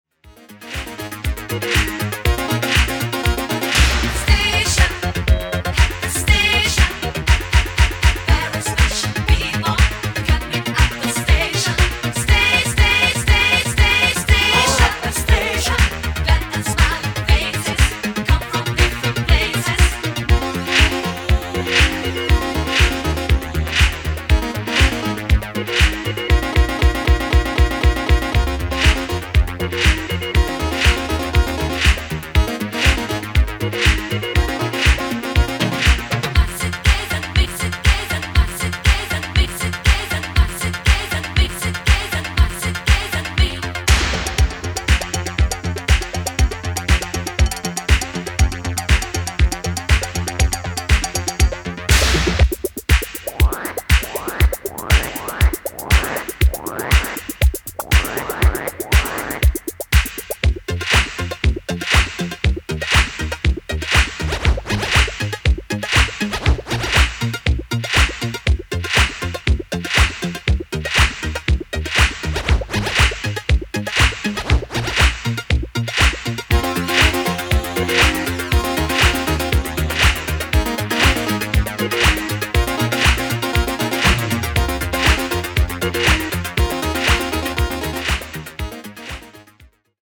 Italo Disco